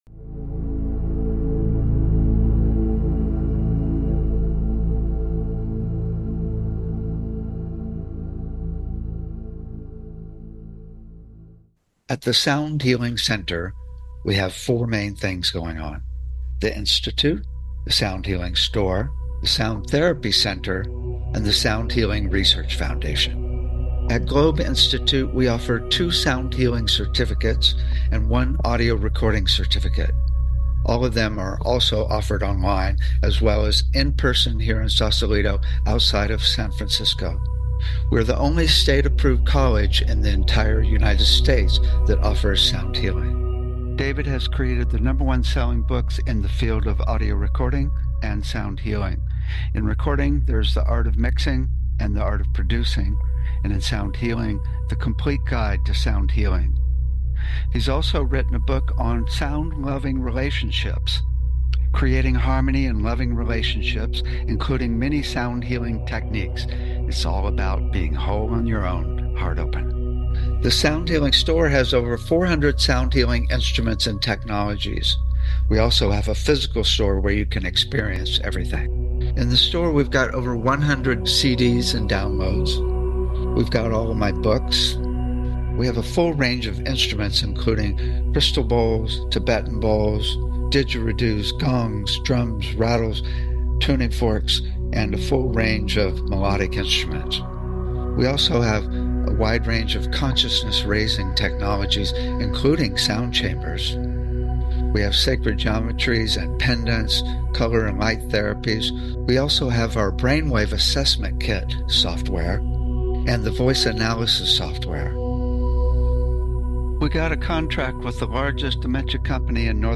Talk Show Episode, Audio Podcast, Sound Healing and Conference Sound Baths on , show guests , about Conference Sound Baths, categorized as Education,Energy Healing,Sound Healing,Love & Relationships,Emotional Health and Freedom,Mental Health,Science,Self Help,Spiritual